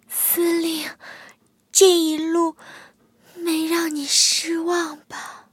M22蝉被击毁语音.OGG